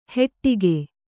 ♪ heṭṭige